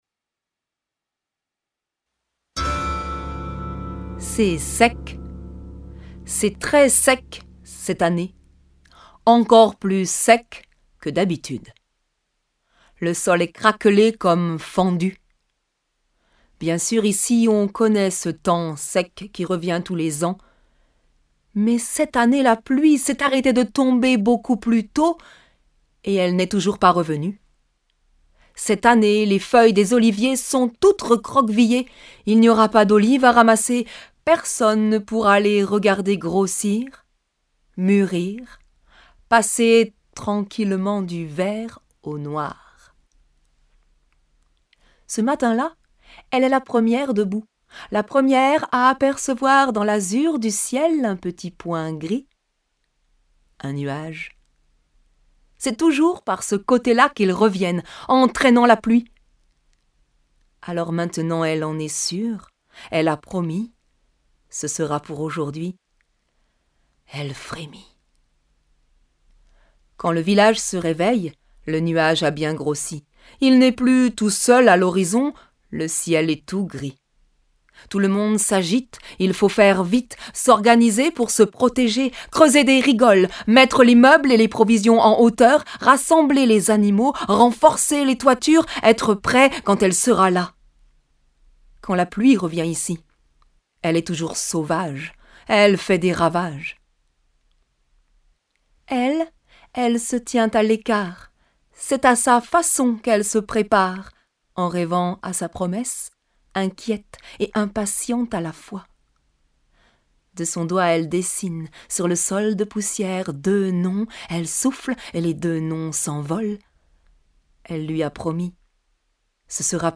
Des contes dits